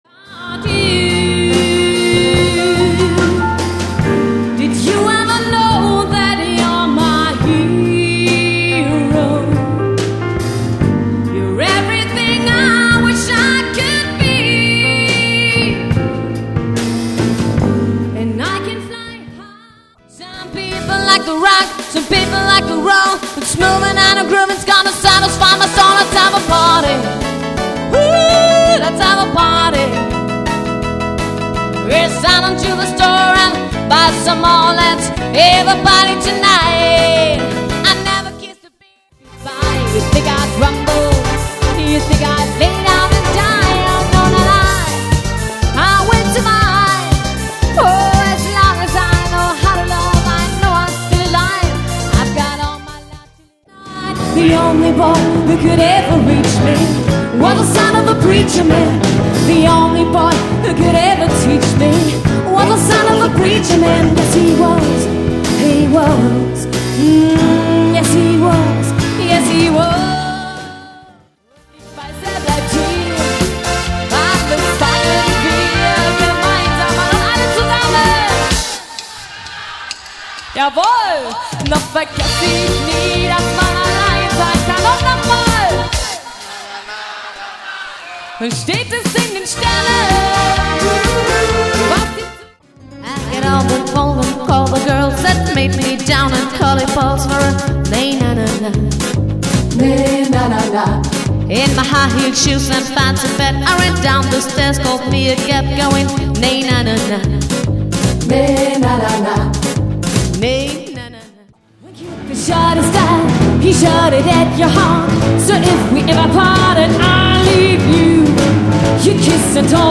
Gefühlvoller Livegesang
Live Demo Sängerin